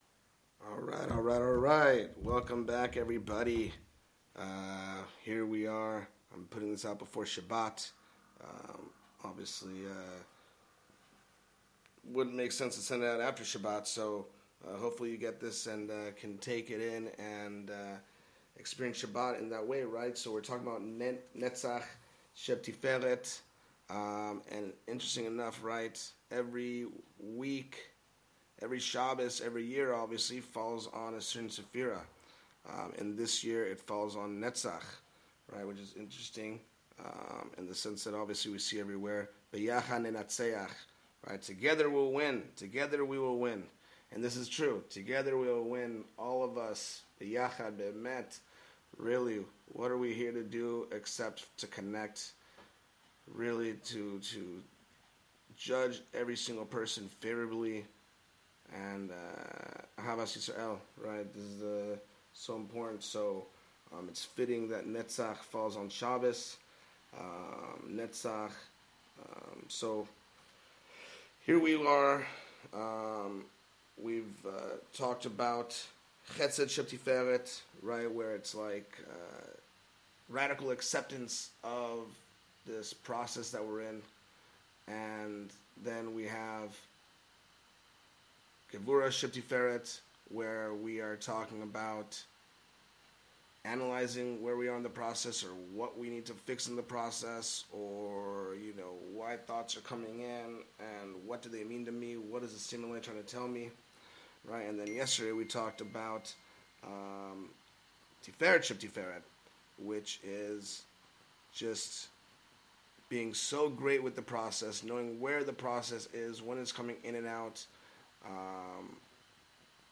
The shiur explores the concept of Netzach Sheb’Tiferet, which is about sustaining harmony and victory in our lives. It emphasizes the importance of daily practice and incremental progress in achieving long-term success.